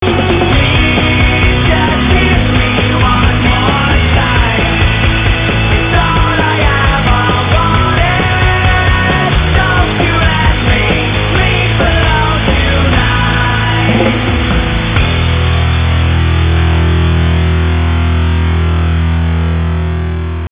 Zvonenia na mobil*(Ringtones):